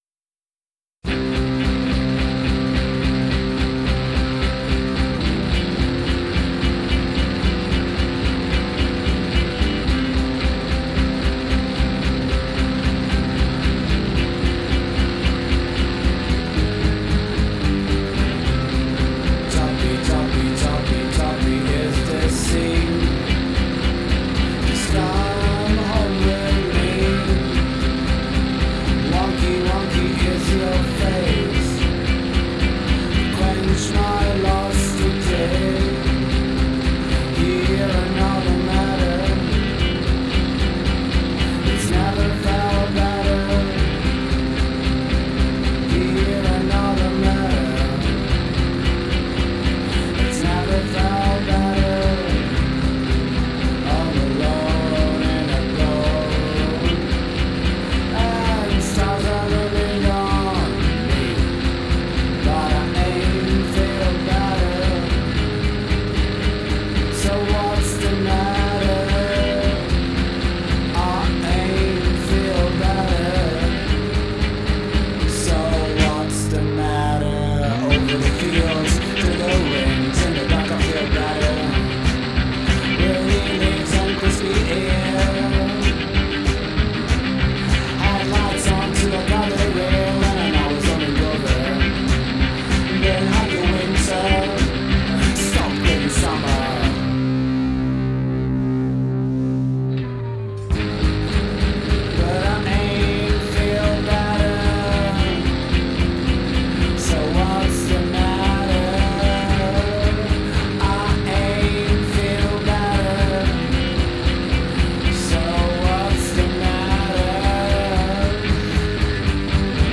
guitar, bass, drum, hi-hat, vocals
lines, fat distorted guitar solos and big fuzzy riffs.